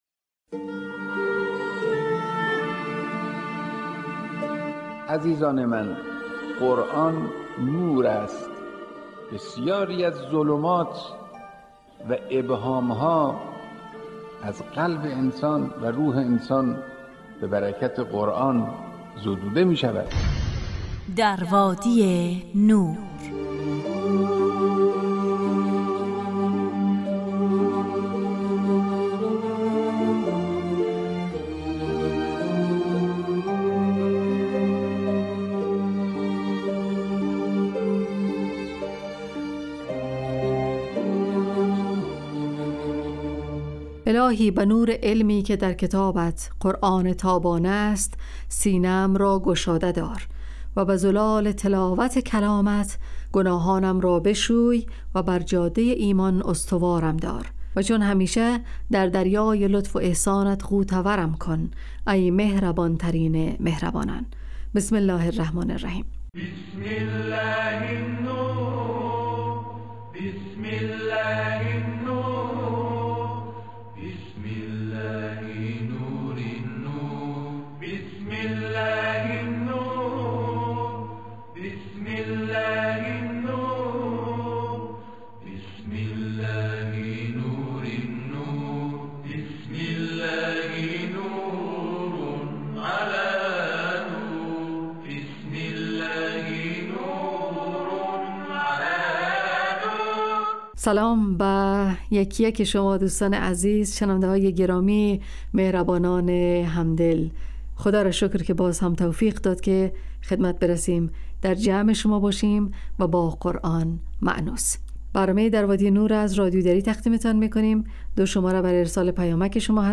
ایستگاه تلاوت